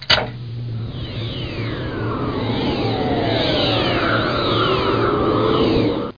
00676_Sound_fan.mp3